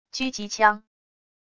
狙击枪wav音频